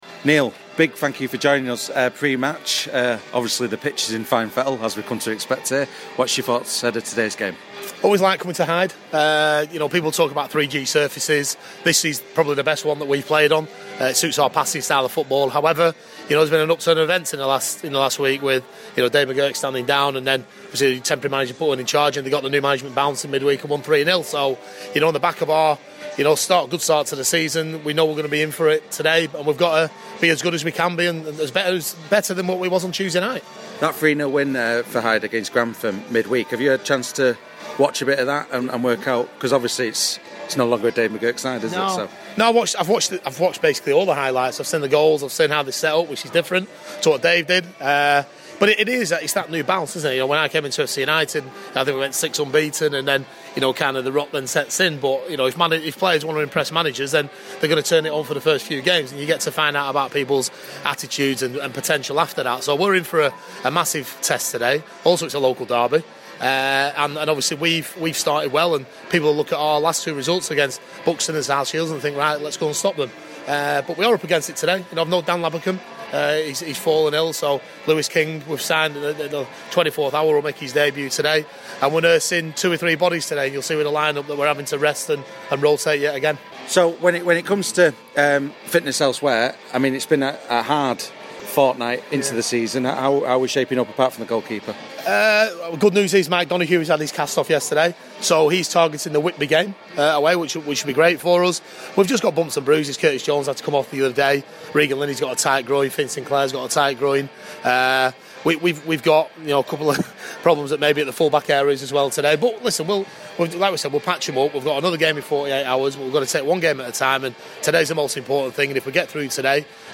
Pre Match Interview